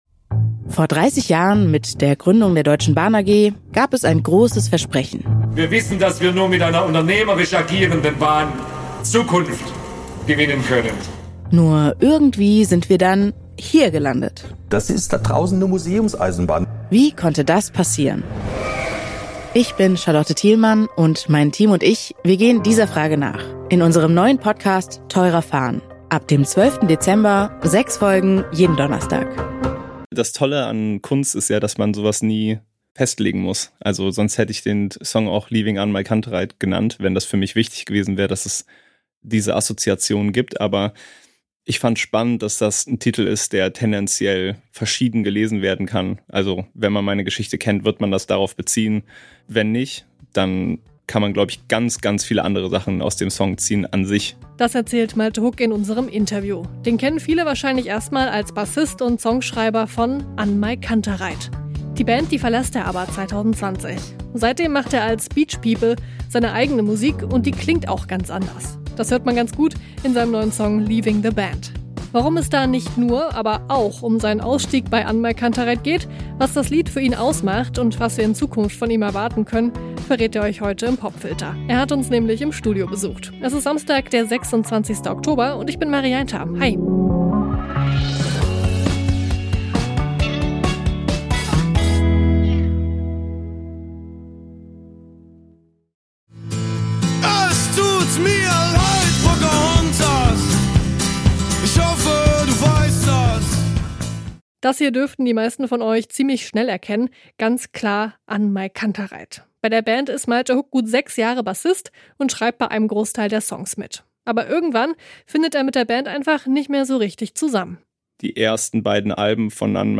Dann steigt er aus und gründet BEACHPEOPLE. Über seine Beweggründe und Zukunftspläne spricht der Musiker im Popfilter.